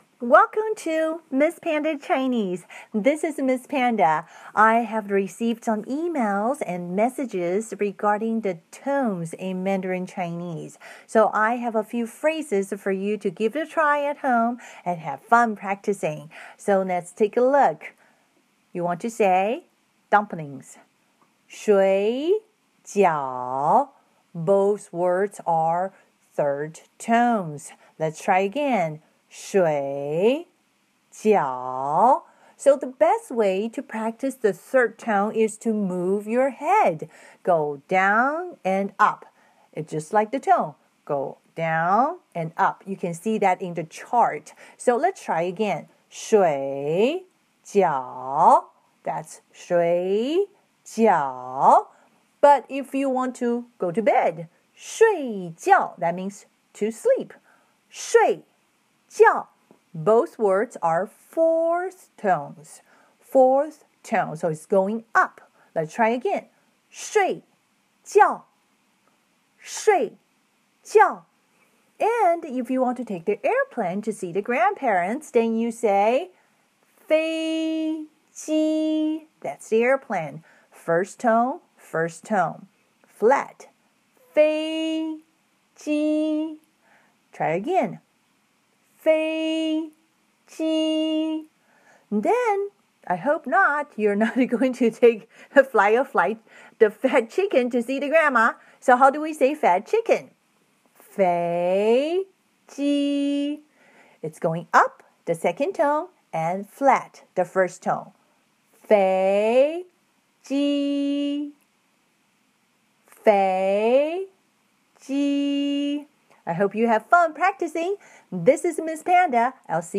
Chinese Tones examples | Miss Panda Chinese Chinese has 4 tones plus a neutral tone.
Miss-Panda-Chinese-Tones-In-chinese-Examples.m4a